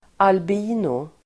Ladda ner uttalet
albino substantiv, albino Uttal: [alb'i:no] Böjningar: albinon, albinoer Definition: varelse som saknar färgämnen i hår, hud och ögon (a human being or animal lacking pigment in its hair, skin and eyes)